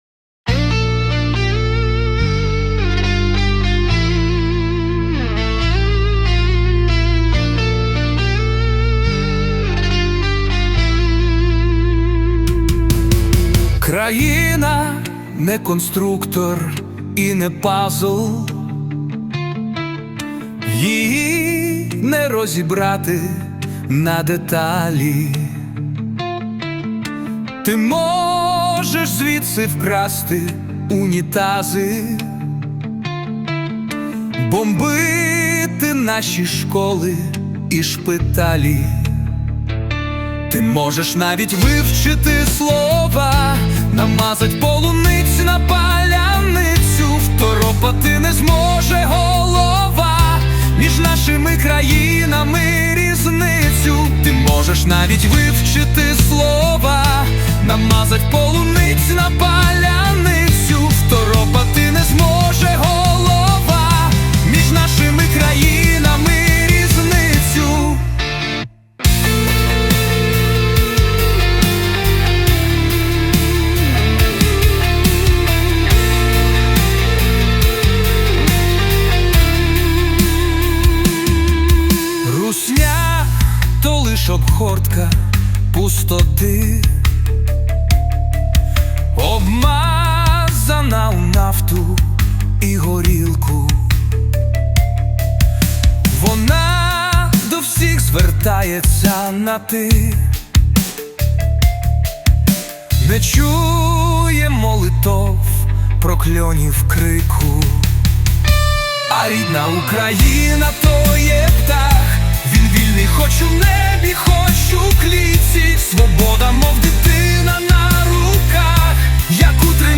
Музика: ШІ.